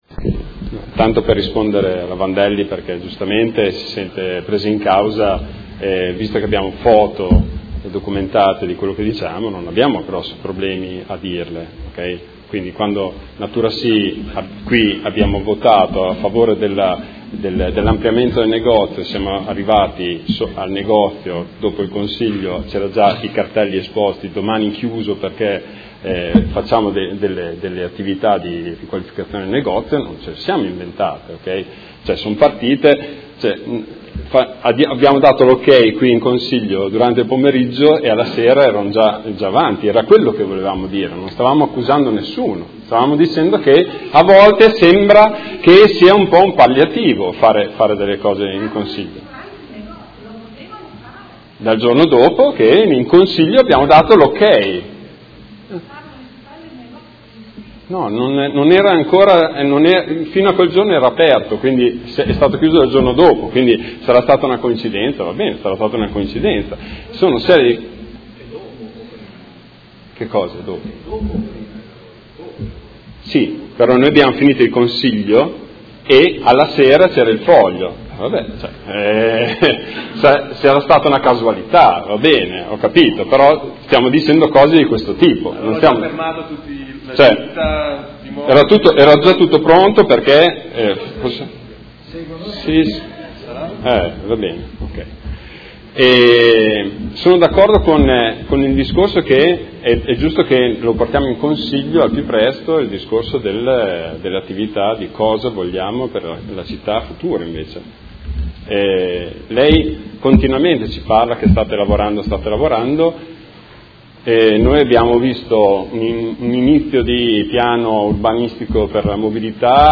Marco Bortolotti — Sito Audio Consiglio Comunale
Dichiarazione di voto